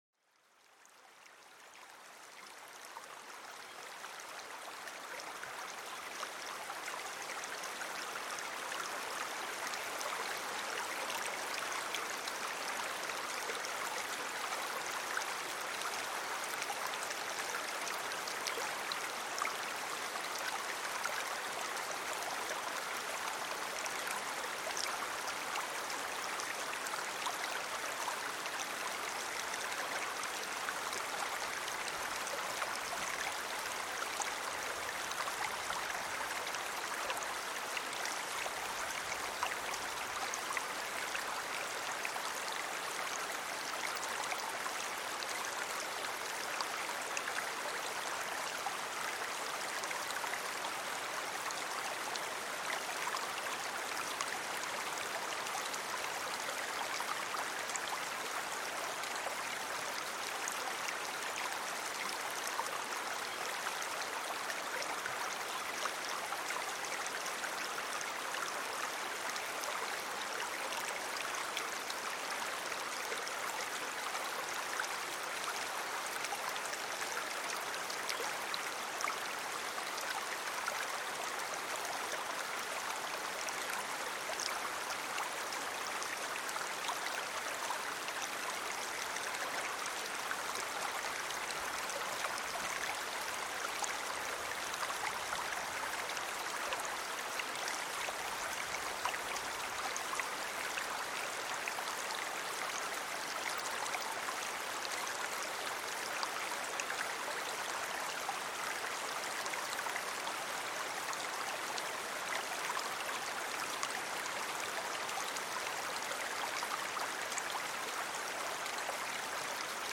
Une rivière apaisante pour un sommeil profond et réparateur